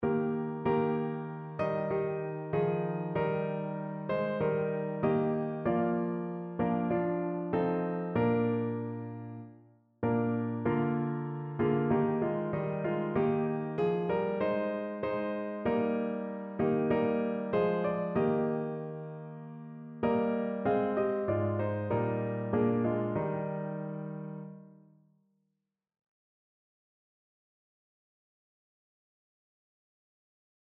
Notensatz 2 (4 Stimmen gemischt)